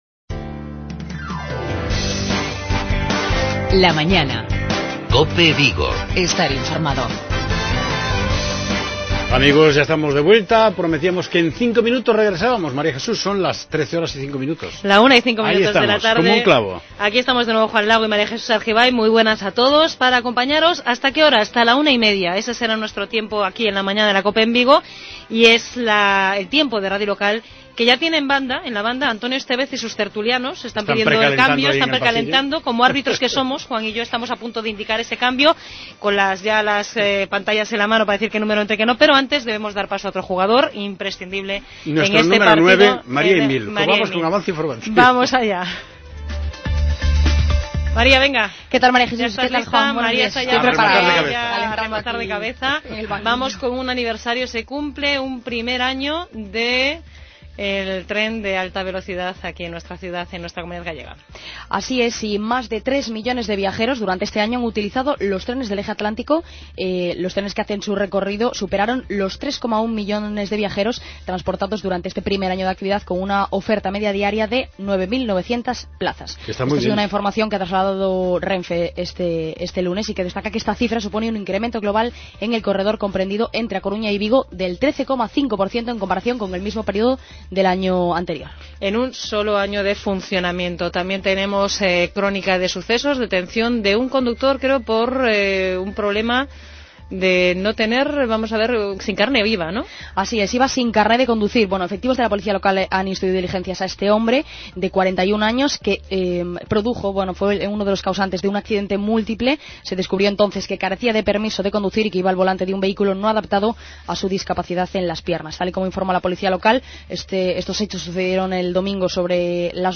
AUDIO: Tertulia deportiva analizando el partido disputado en Balaídos el pasado sábado, que deparó un empate del Celta frente al Betis....